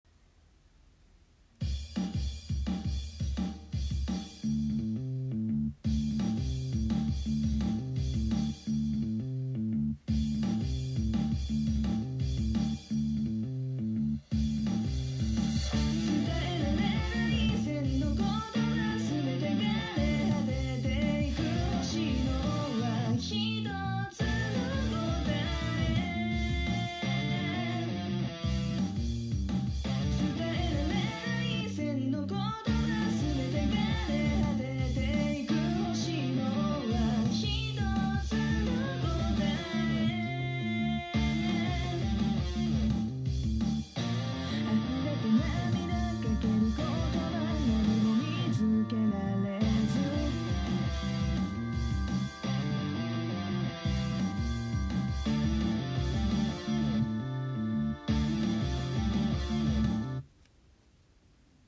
Google Homeに比べると低音が全然違いますし、音の厚みも違います。